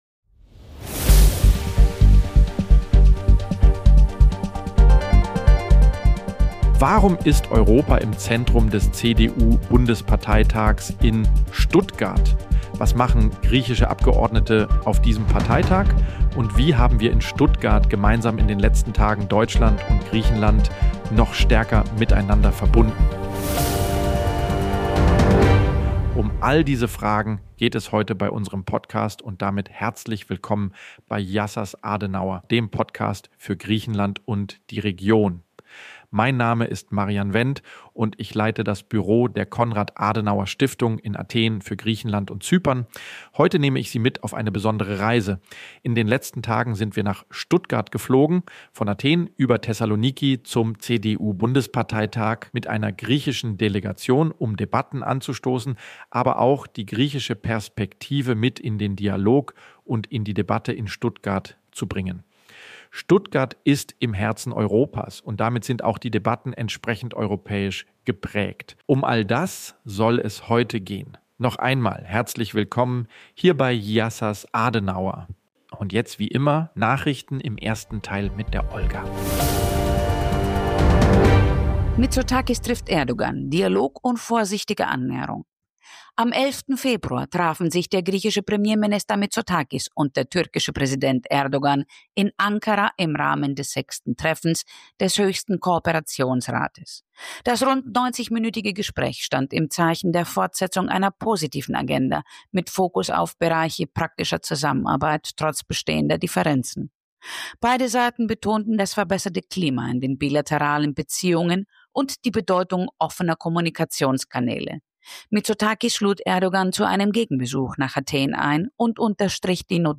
Griechische Perspektive vor Ort: Austausch mit Abgeordneten, Beratern und Partnern aus Griechenland